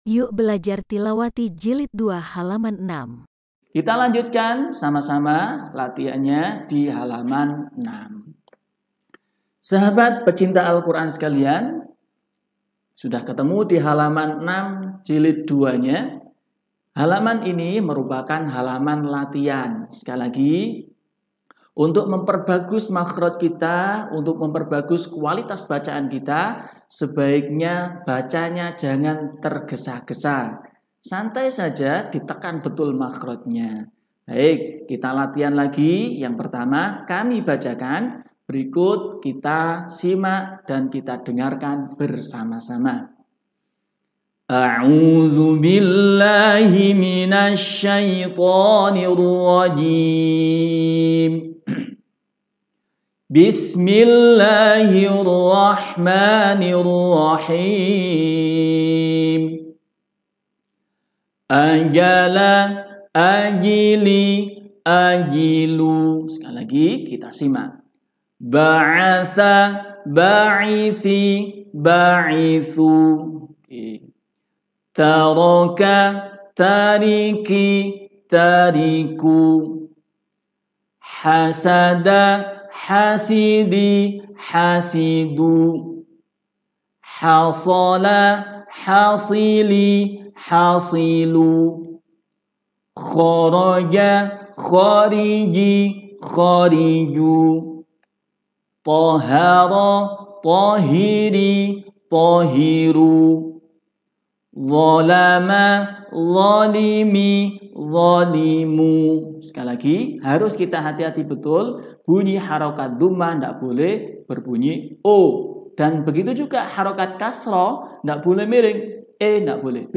Yuk Belajar Tilawati Jilid 2 Halaman 6 Irama Rost